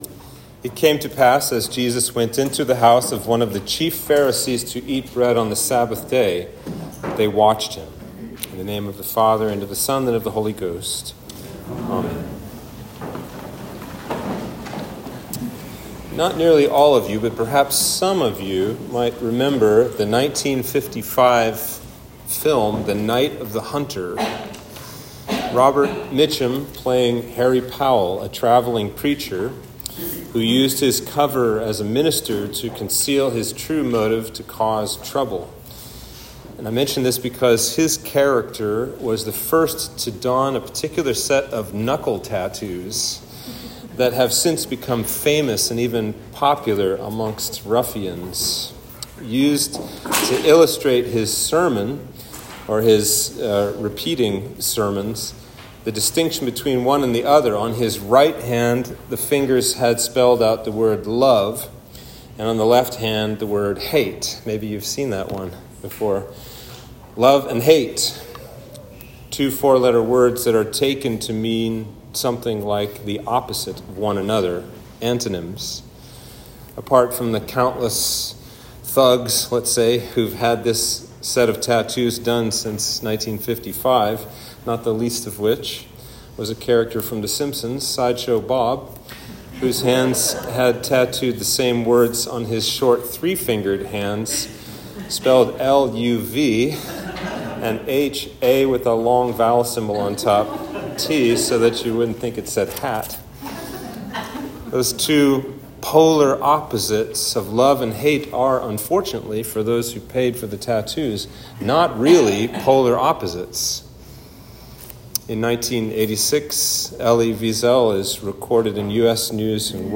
Sermon for Trinity 17